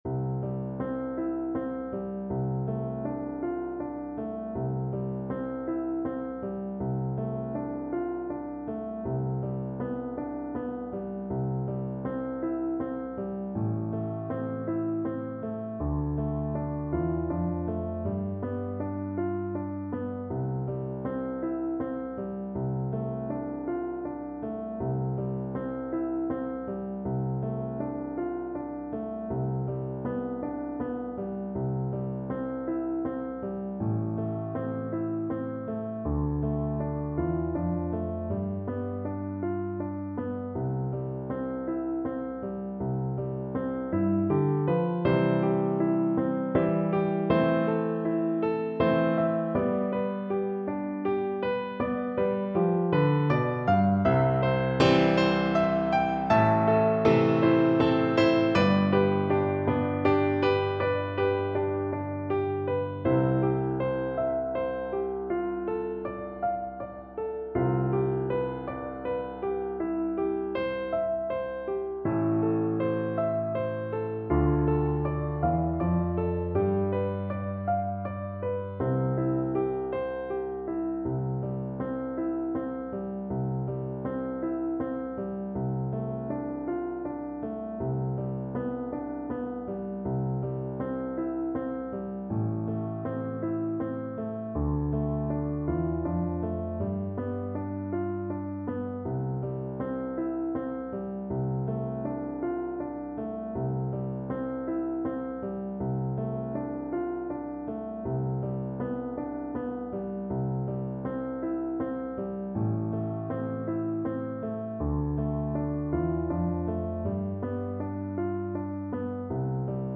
Free Sheet music for Voice
Play (or use space bar on your keyboard) Pause Music Playalong - Piano Accompaniment Playalong Band Accompaniment not yet available transpose reset tempo print settings full screen
Voice
C major (Sounding Pitch) (View more C major Music for Voice )
Gently flowing =c.80
3/4 (View more 3/4 Music)
A4-F6
Traditional (View more Traditional Voice Music)